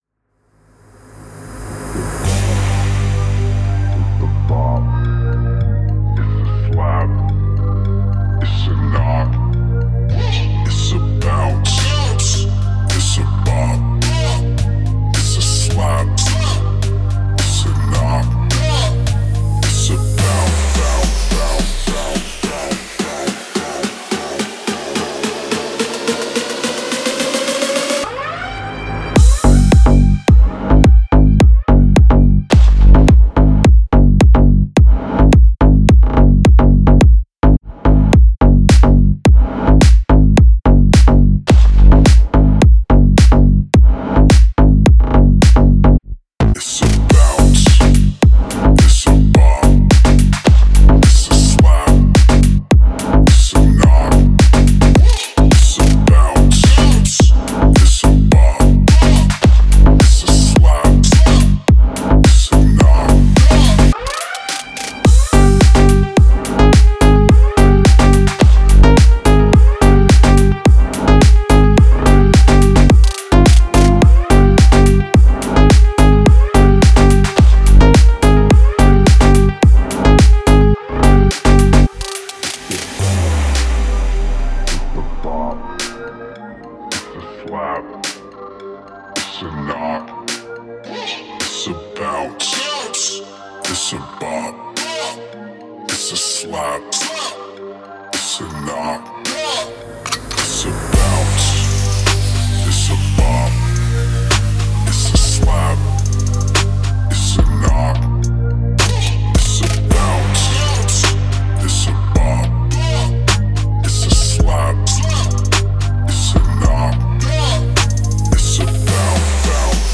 это динамичная и энергичная композиция в жанре EDM